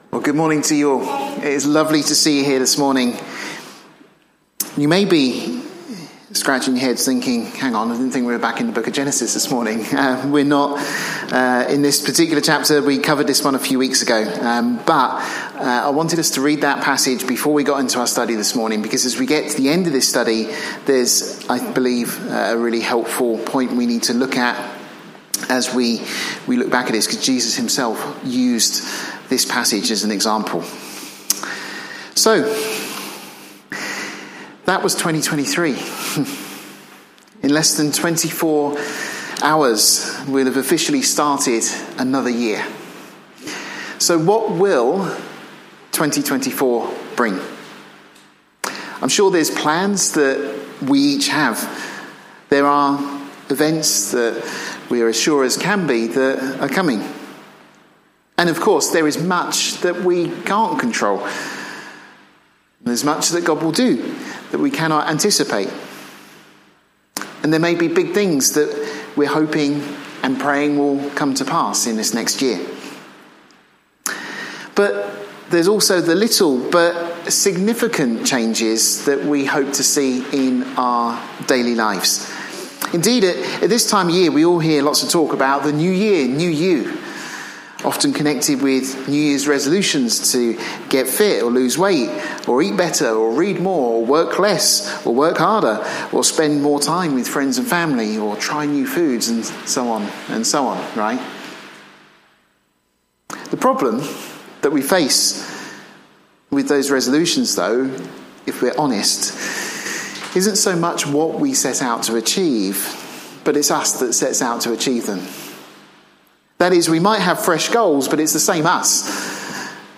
Topical Bible studies